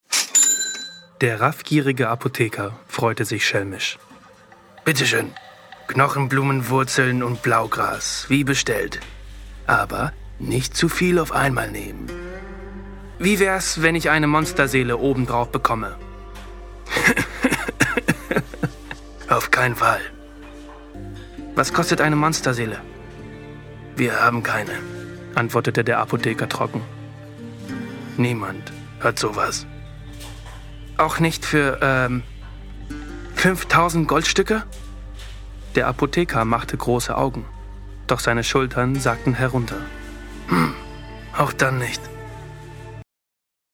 sehr variabel, hell, fein, zart, markant
Mittel minus (25-45)
Sprachprobe Erzähler Deutsch
Audiobook (Hörbuch), Audio Drama (Hörspiel)